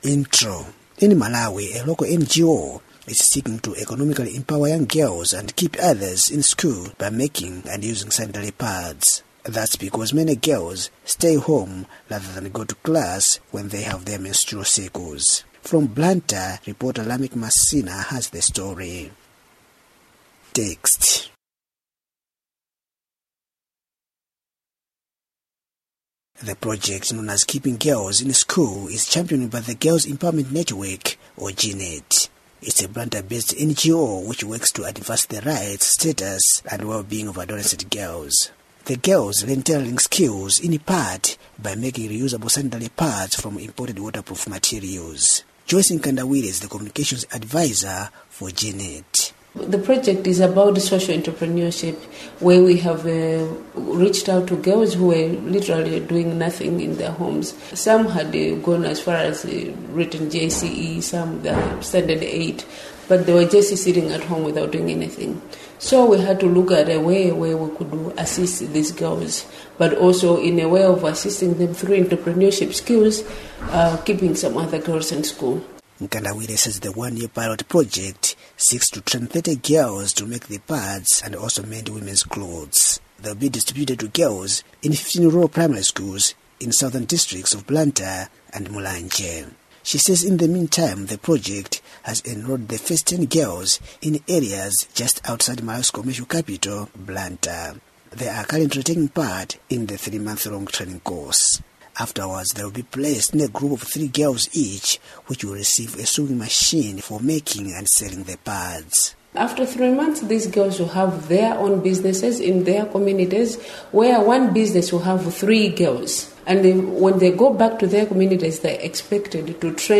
Listen to report on sewing project for girls in Malawi